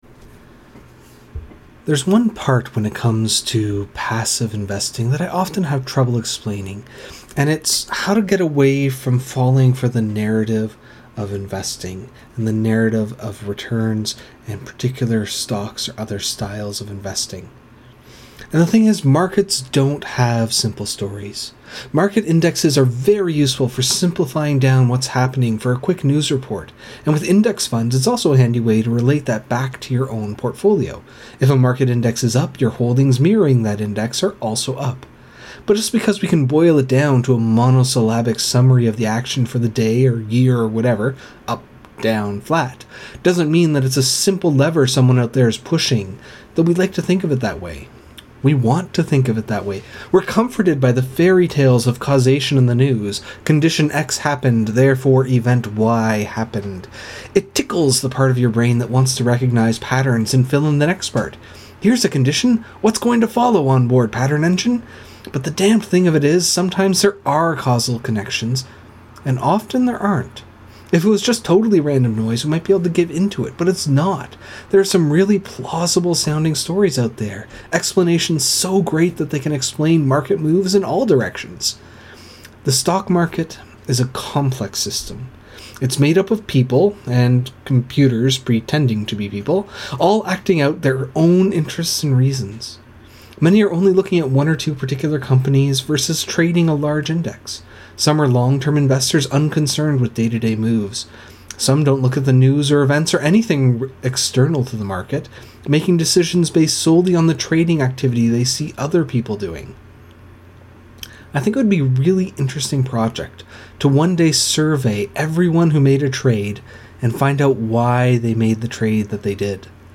This is a recording of a rant I put together originally for the DIY investing course, but it didn’t quite fit the tone of the course.
Passive_Investing_Nonlinear_Rant.mp3